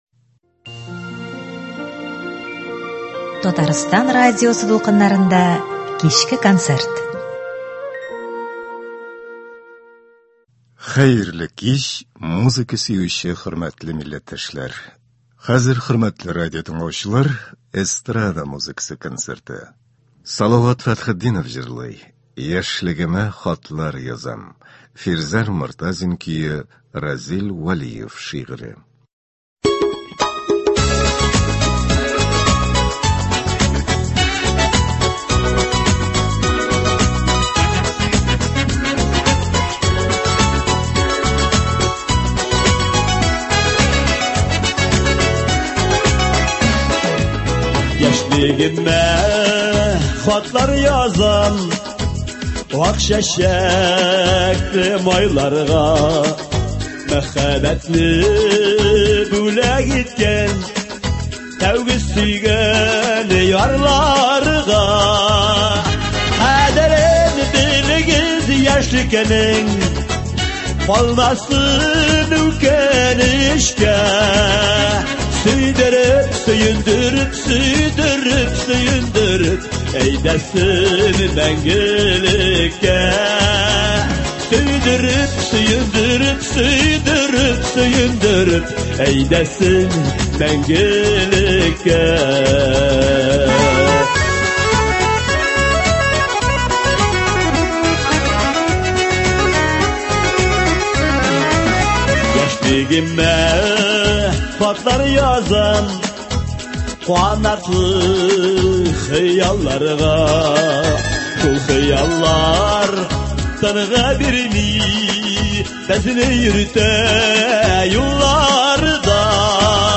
Бүгенге кичке эфирда - эстрада концерты.